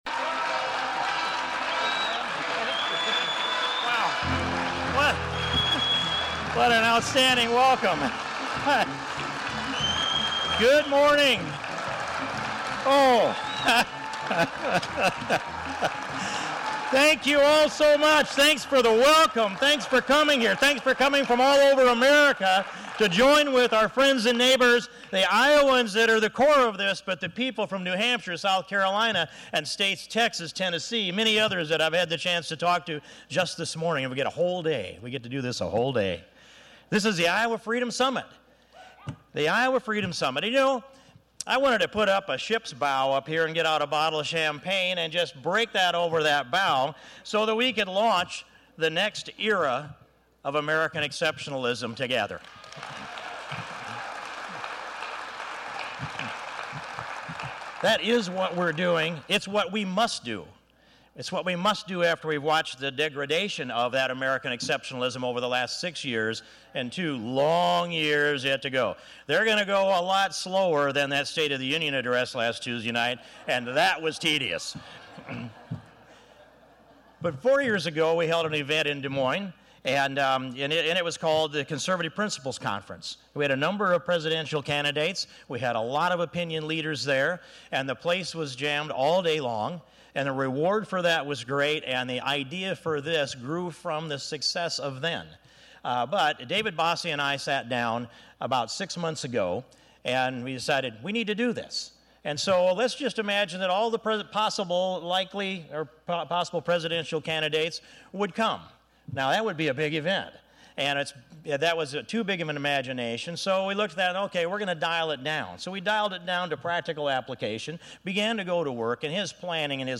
Congressman Steve King opened the “Iowa Freedom Summit” this morning in Des Moines by suggesting the crowd will hear from one of the Republican candidates who will win the presidency in 2016.
King, who had the first speech slot of the day, laid out a few issues which he expects the possible candidates to address today, including national defense. When King called for abolishing the IRS he got a rousing response from the crowd.